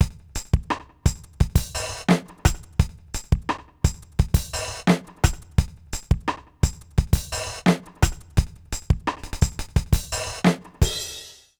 British REGGAE Loop 087BPM.wav